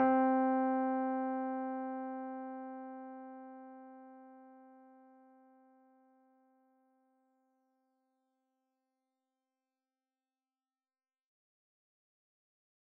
Organ (C).wav